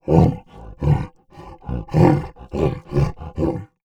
MONSTER_Effort_01_mono.wav